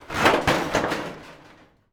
metal_sheet_impacts_17.wav